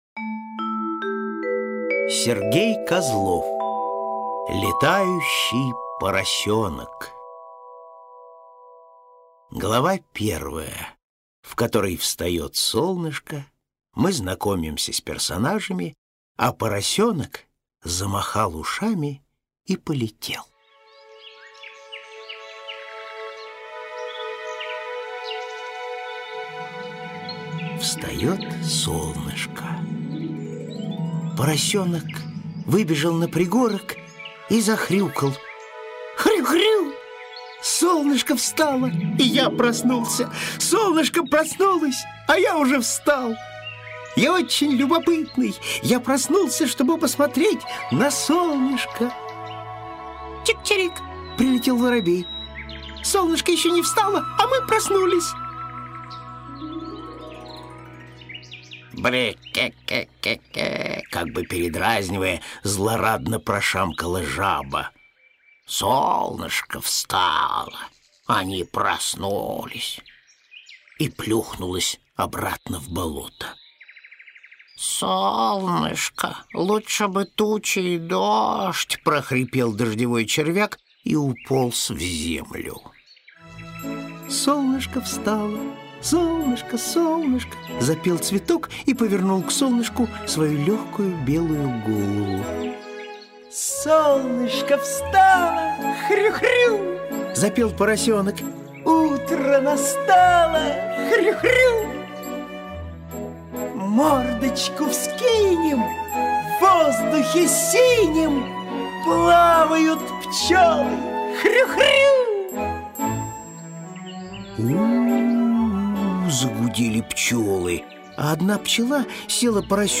Аудиокнига Летающий поросёнок | Библиотека аудиокниг